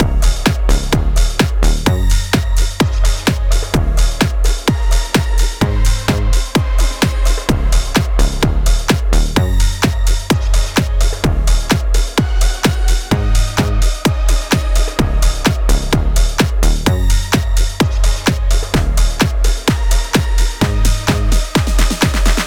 Happy
Db Major
Plucky Hop
Small Fun